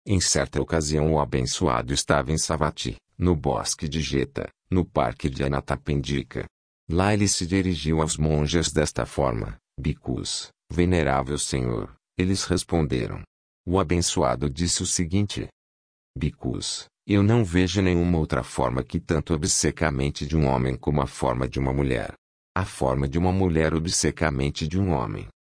In terms of speed, could we have it slowed down by a 0.1x factor?
Ricardo -10%